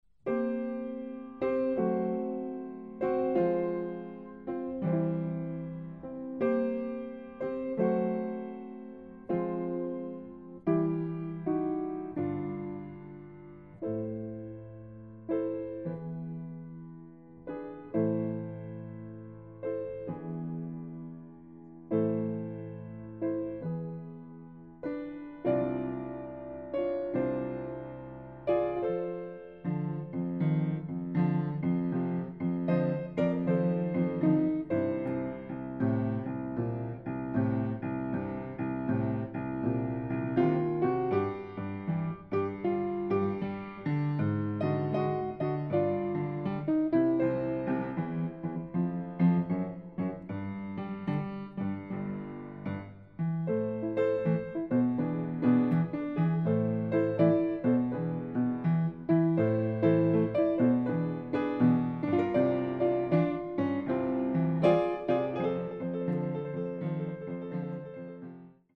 Style: Barrelhouse Piano